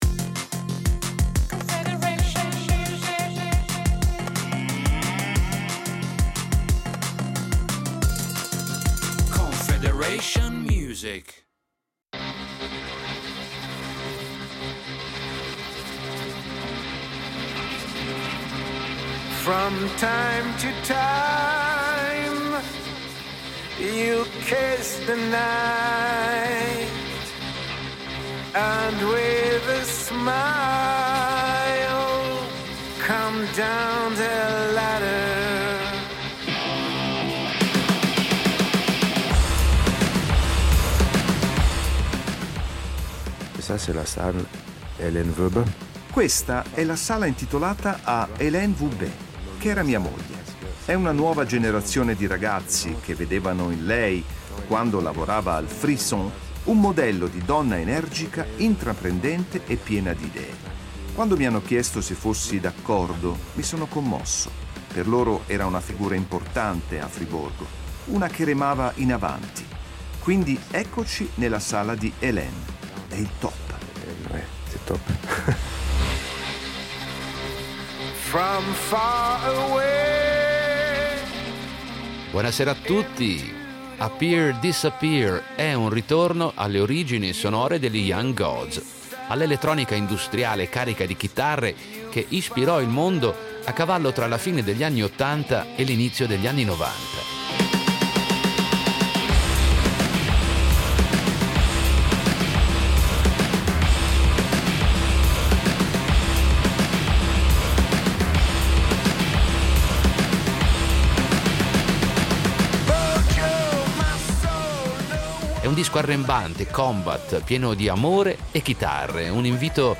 Musica rock
Franz Treichler, gentiluono d’altri tempi, ci ha accolto in una sala del rinnovato Cinema Korso di Friborgo